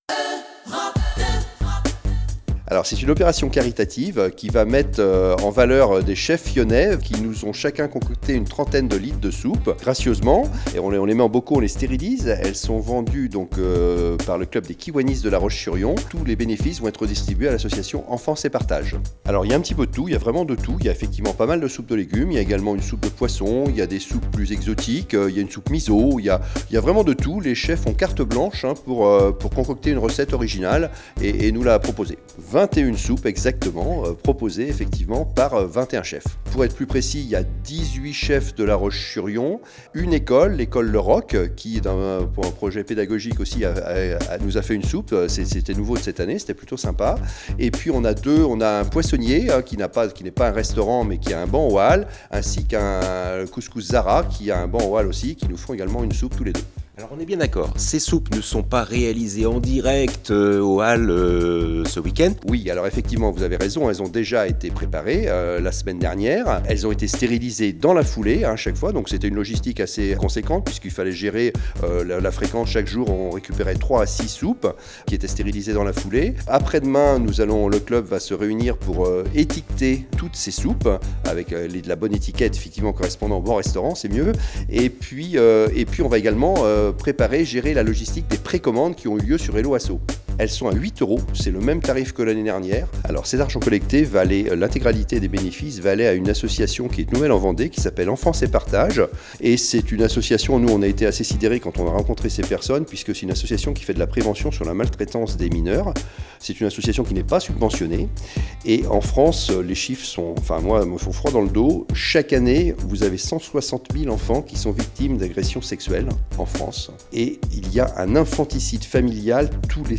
(Kiwanis) vous dit tout, au micro EUROPE 2